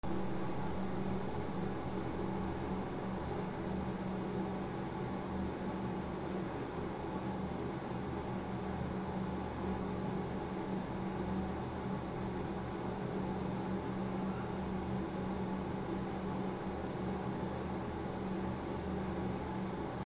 / C｜環境音(人工) / C-25 ｜部屋、ルームトーン
図書館 トイレ 換気扇 環境音